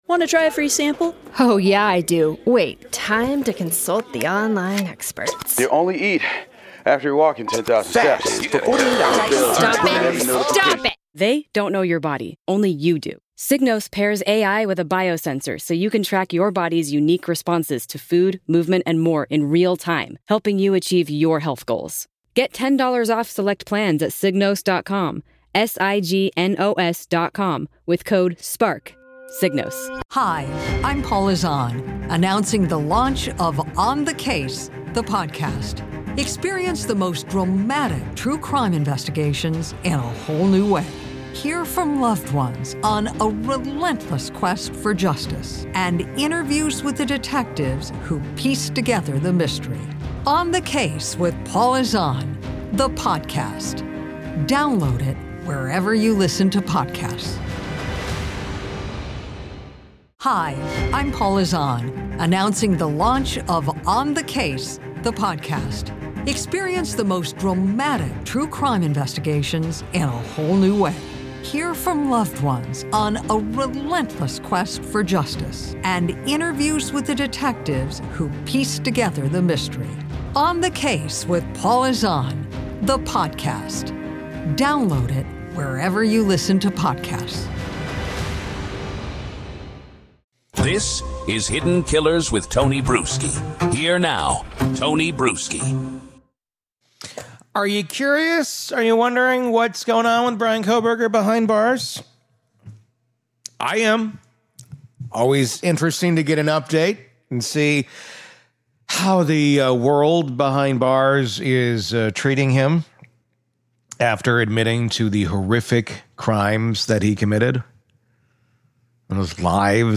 with the signature Hidden Killers voice: sharp, emotionally grounded, and relentlessly focused on truth over spectacle.